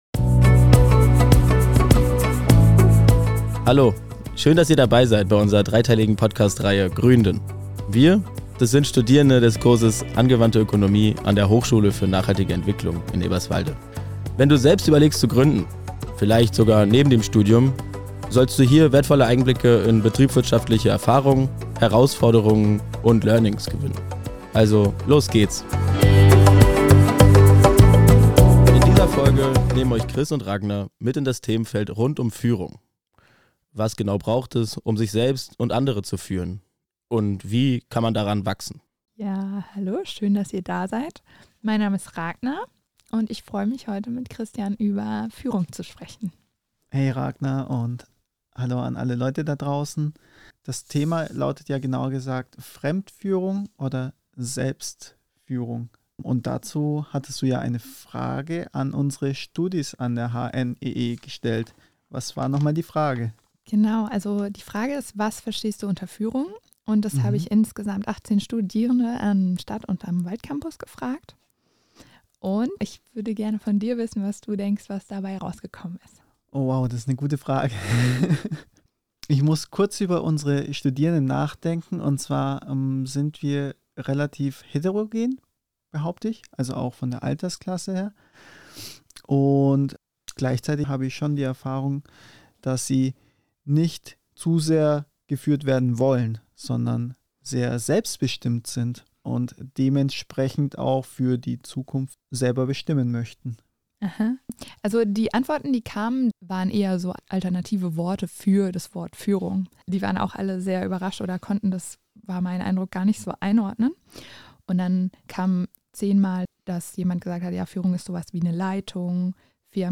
Musik: Musicfox